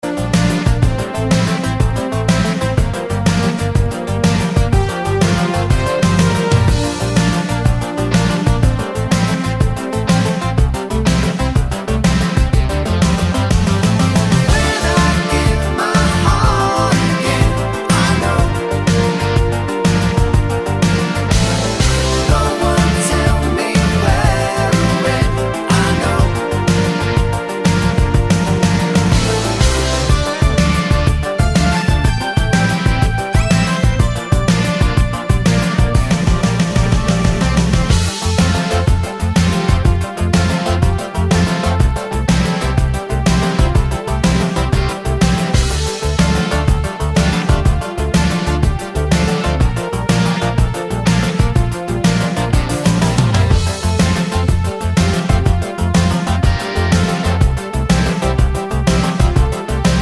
no sax Pop (1980s) 4:47 Buy £1.50